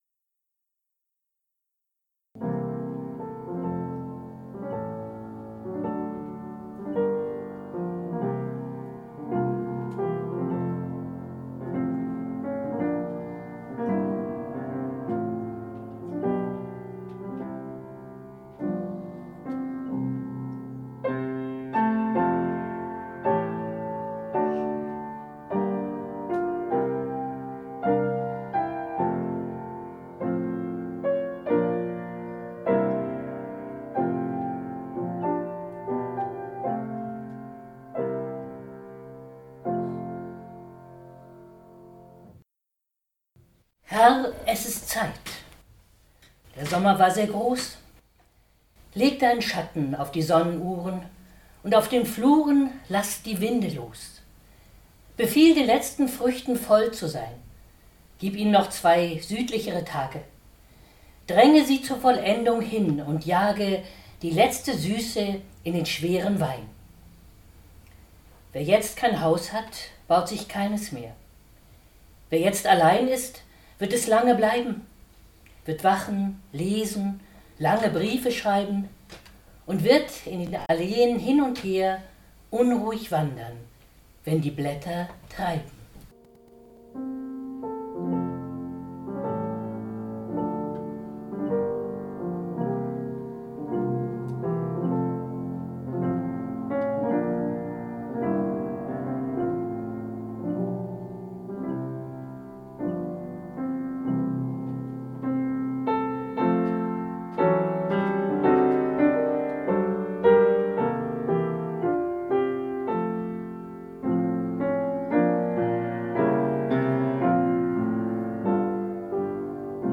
Mitschnitt einer öffentlichen Veranstaltung (MP3)